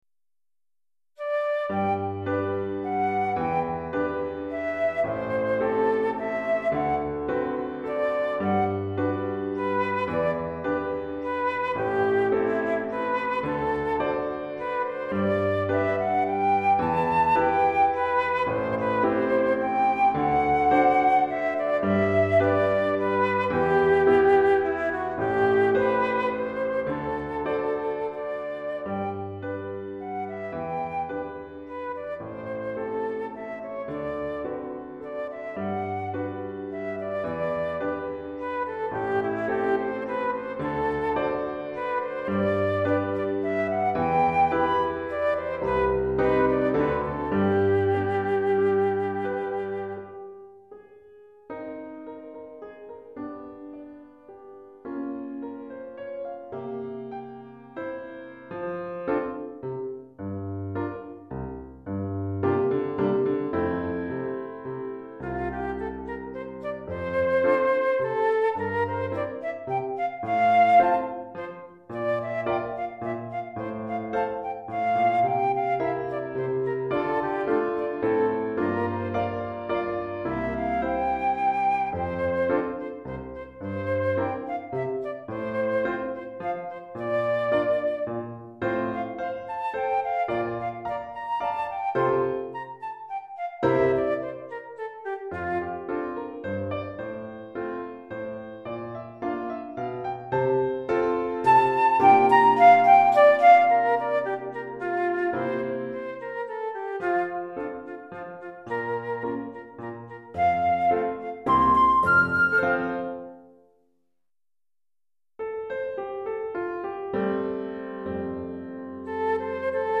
Pour flûte et piano DEGRE FIN DE CYCLE 1